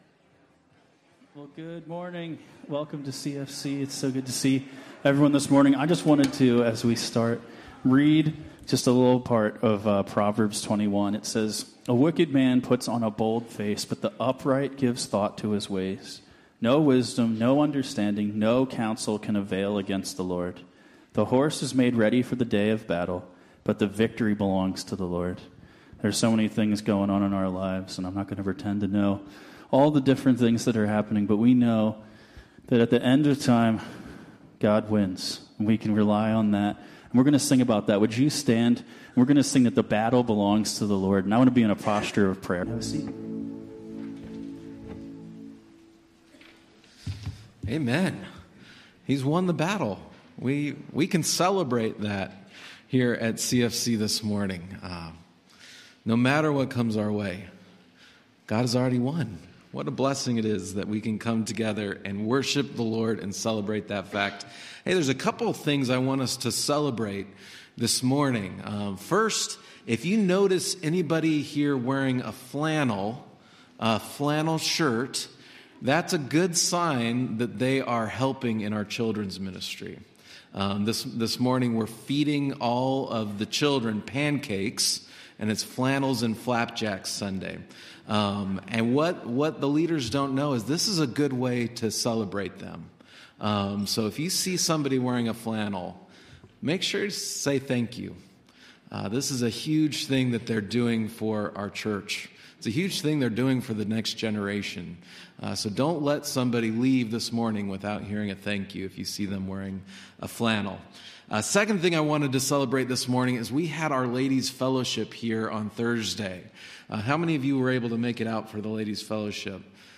Sermons | Christian Fellowship Church